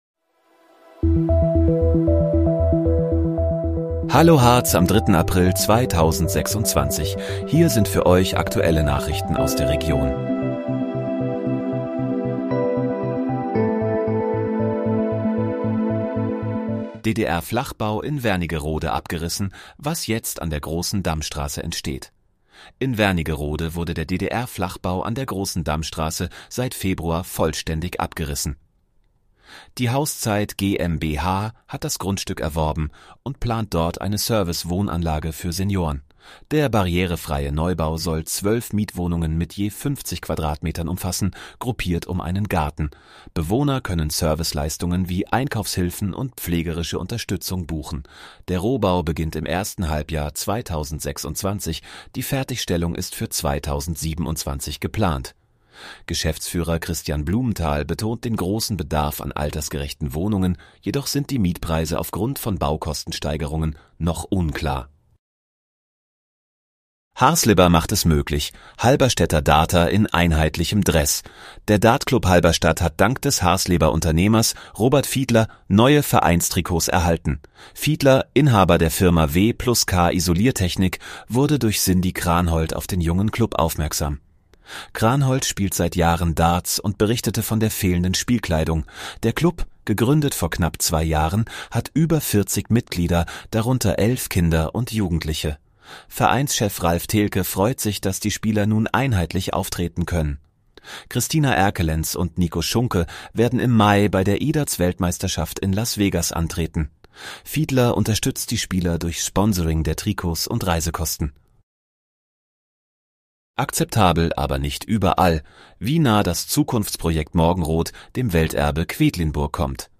Hallo, Harz: Aktuelle Nachrichten vom 03.04.2026, erstellt mit KI-Unterstützung